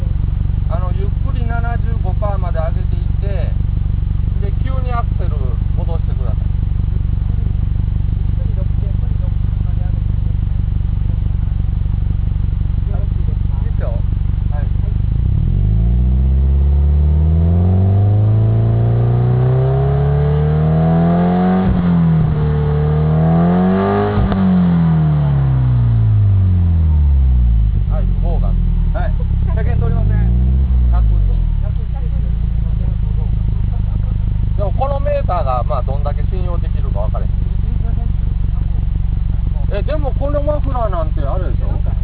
排気音測定オフ？